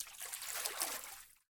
fishLine1.wav